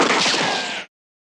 Roland.Juno.D _ Limited Edition _ GM2 SFX Kit _ 20.wav